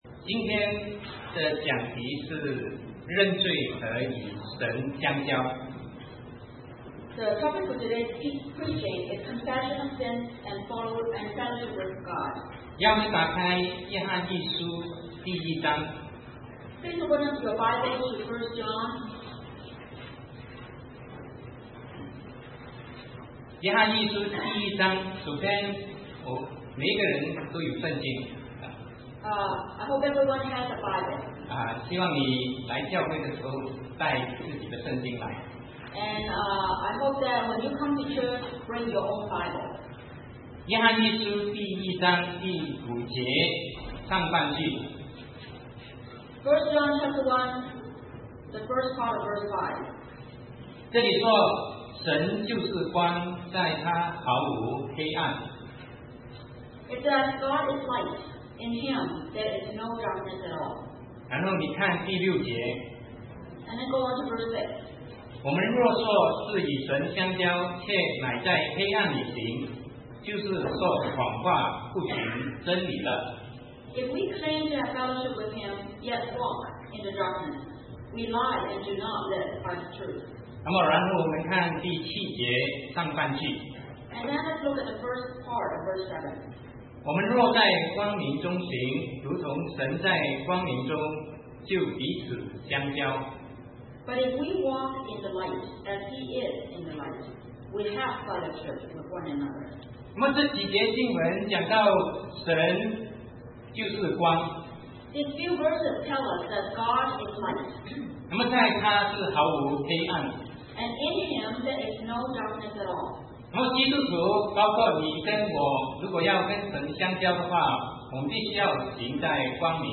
Sermon 2009-08-09 Confession of Sins and Fellowship with God